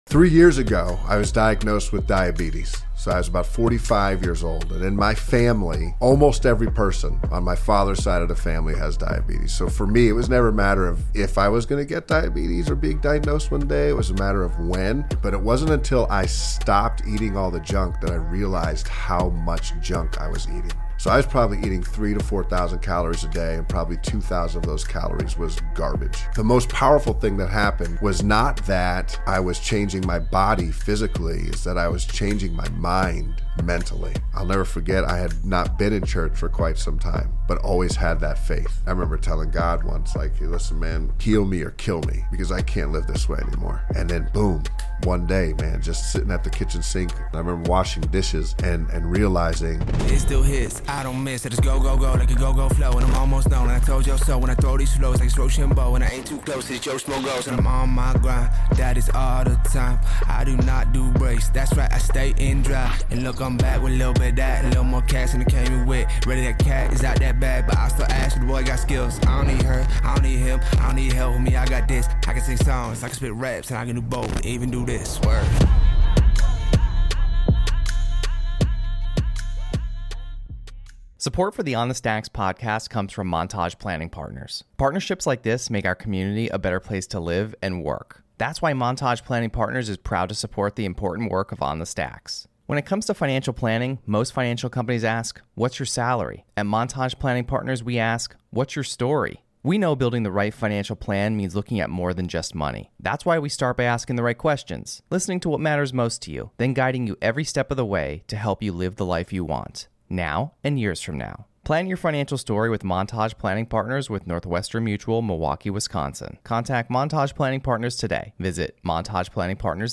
in the MPP studio